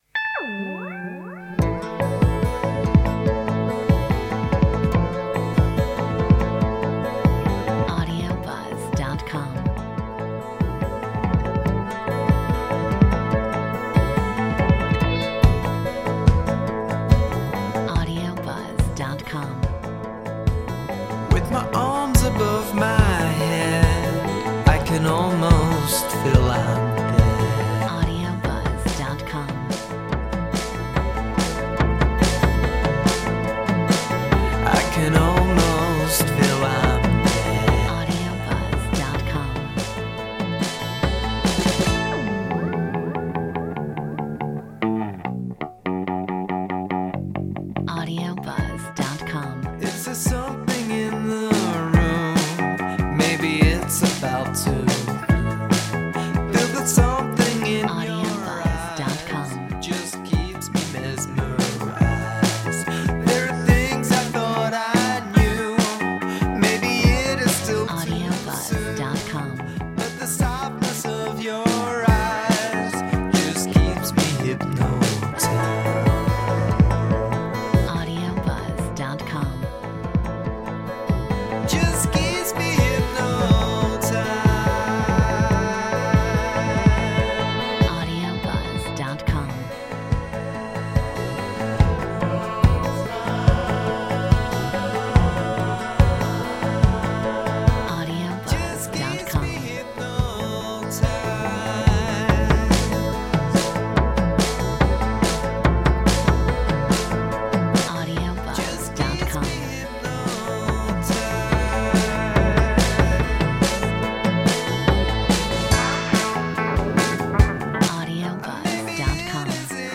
Metronome 143